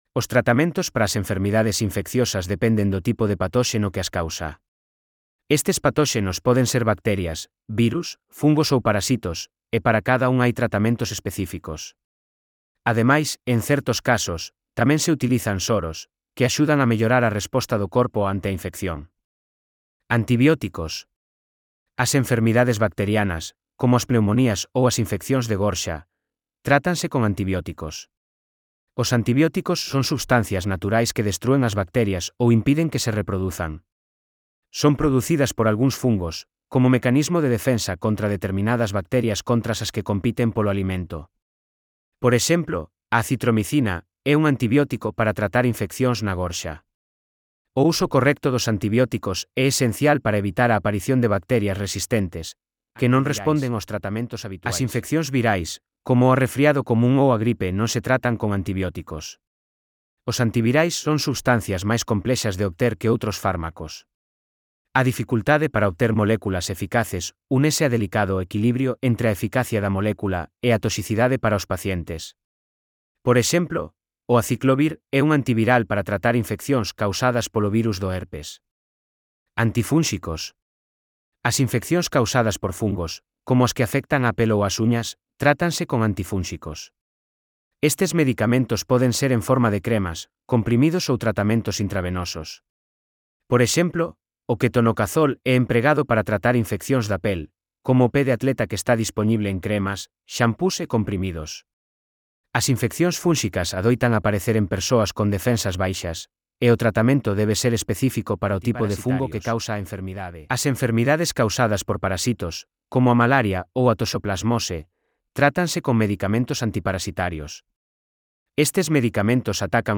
Elaboración propia coa ferramenta Narakeet. Audio (CC BY-SA)